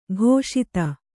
♪ ghōṣita